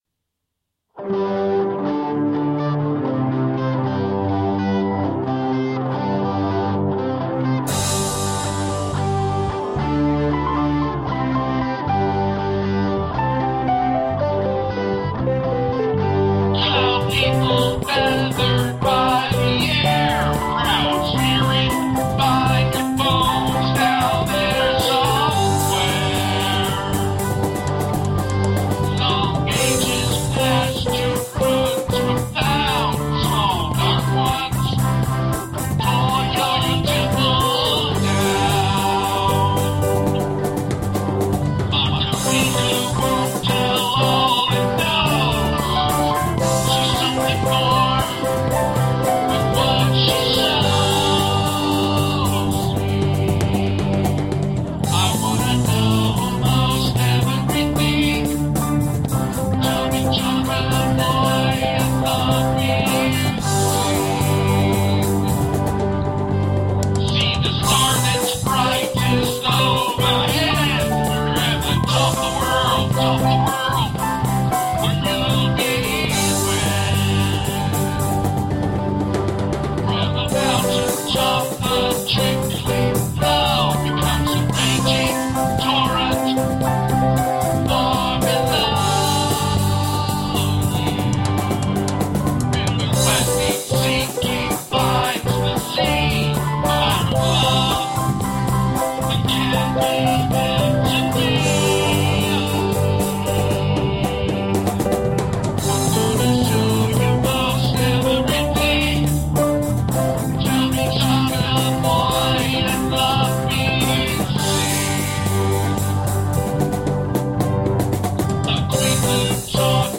What I DON'T like: performance/timing errors. My vocals. What I DO like: How multi-tracking can make me sound like a real piano player, kind of.